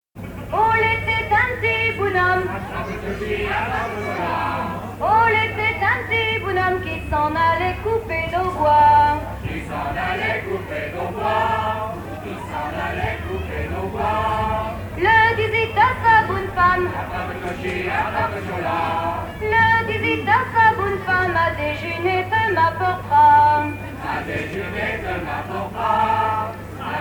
Genre laisse
Veillée à Champagné
Pièce musicale inédite